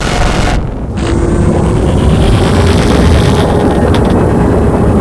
distortion.wav